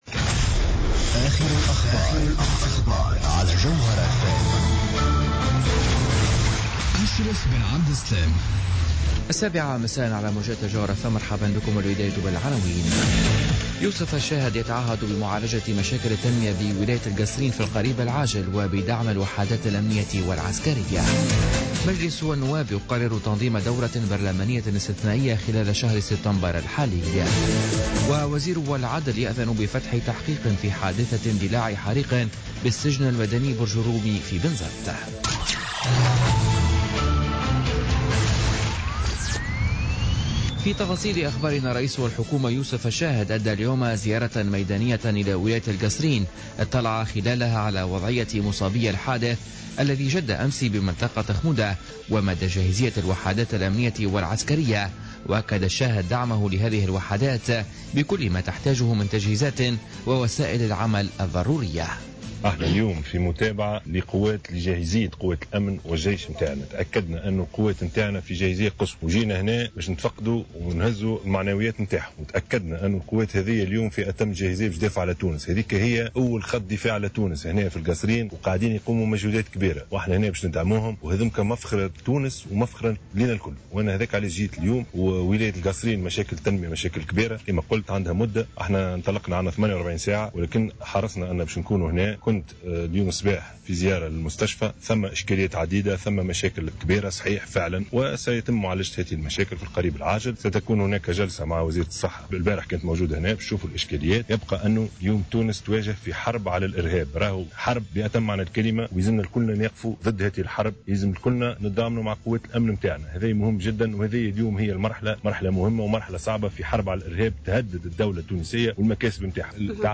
Journal Info 19h00 du Jeudi 1er Septembre 2016